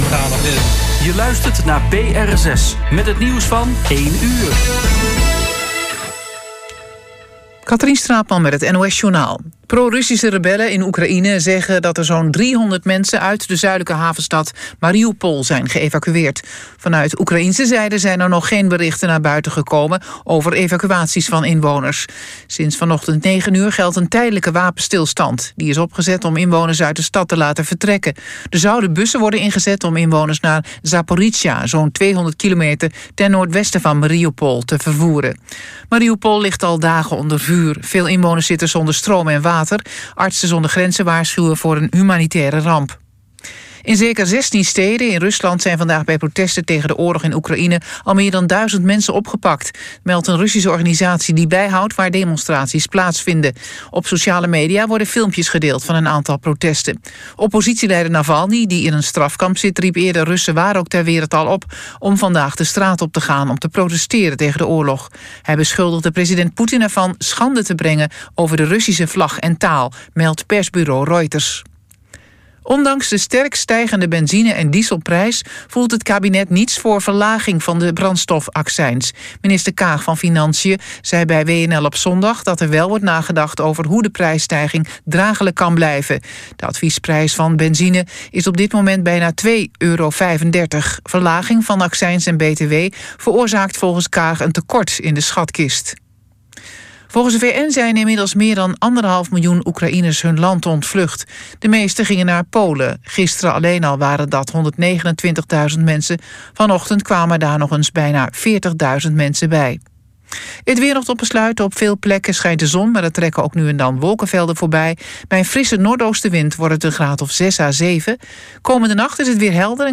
SOLO-ZANG EN DIRIGENT